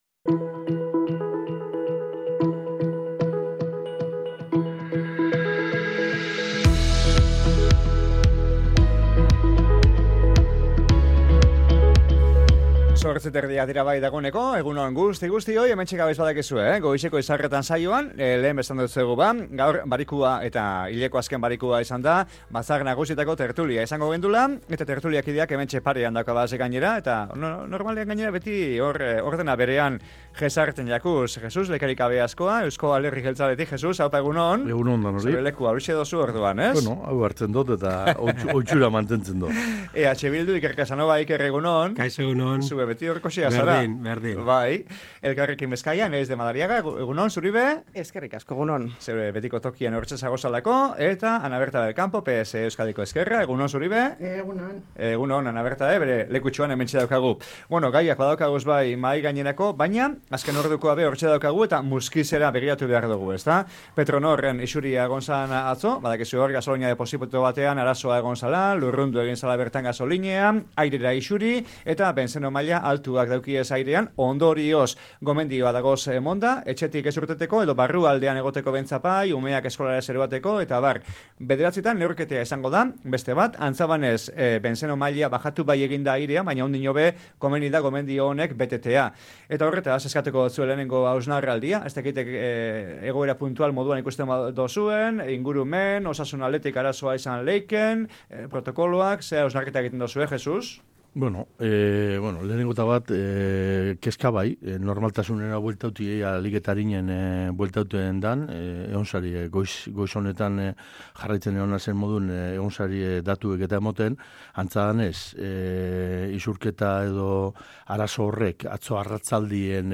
Petronorren isuriaren gainekoak gaur Bizkaiko Batzar Nagusien tertulian | Bizkaia Irratia
BATZAR-NAGUSIAK-TERTULIA.mp3